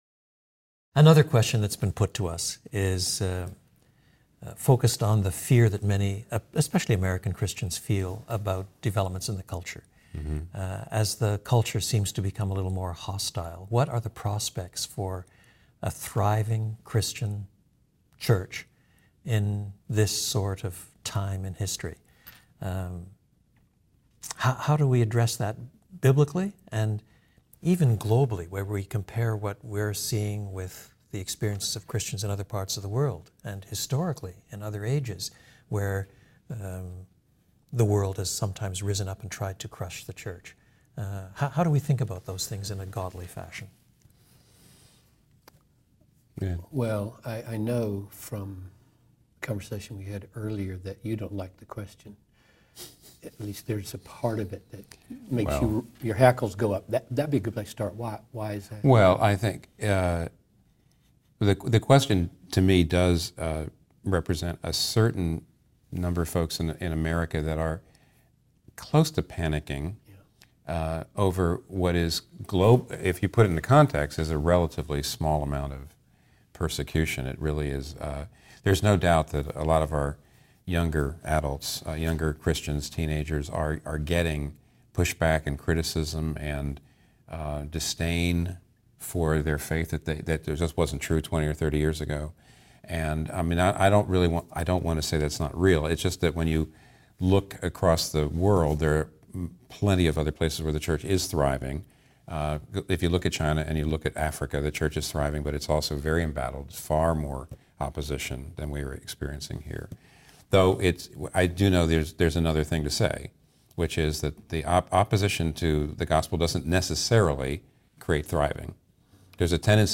In this roundtable video discussion, Tim Keller, John Piper, and Don Carson consider the prospects of a thriving church in an increasingly hostile culture.